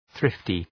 Προφορά
{‘ɵrıftı}